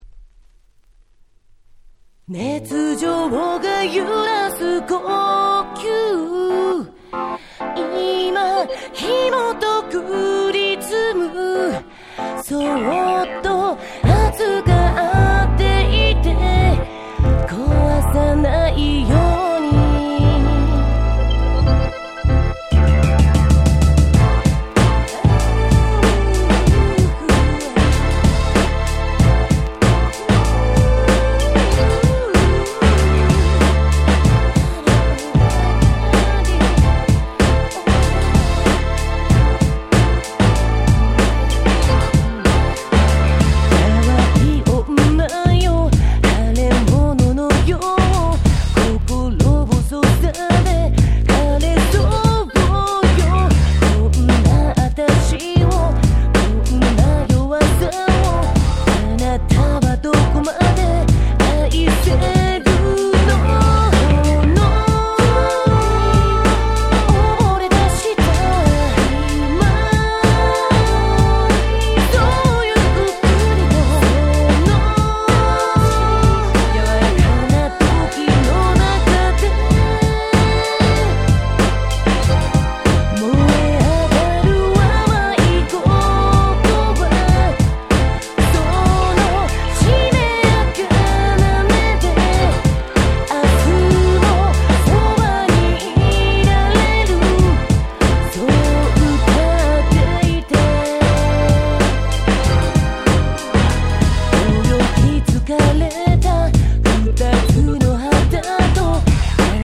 00' Nice Japanese R&B !!